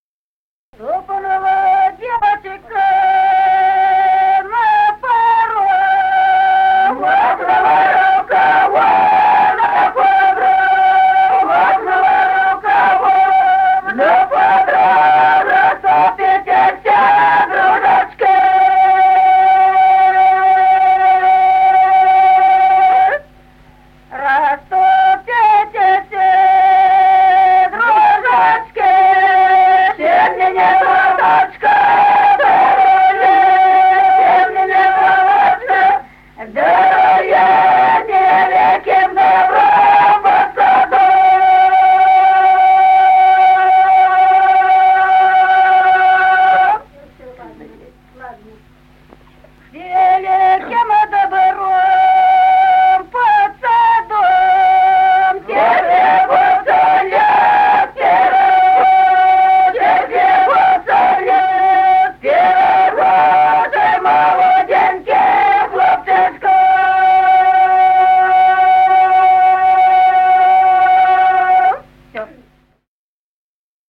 Песни села Остроглядово. Ступнула девочка на порог.